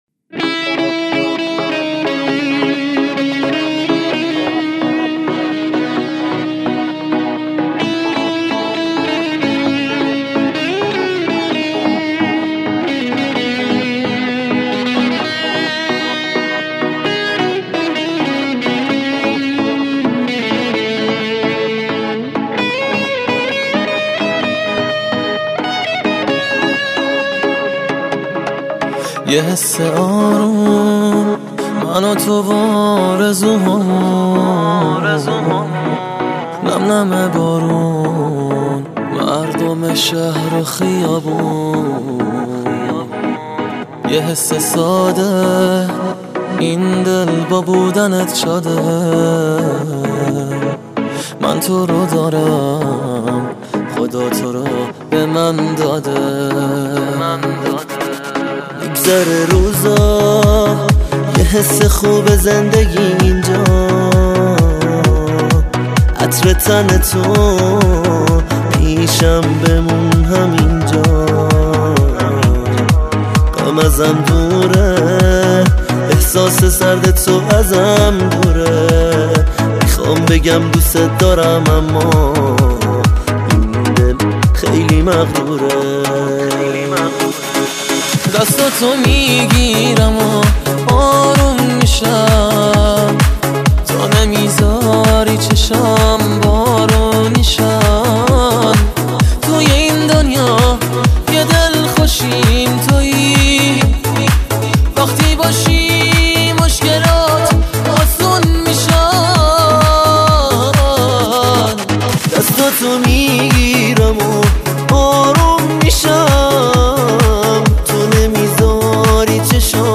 گیتار الکتریک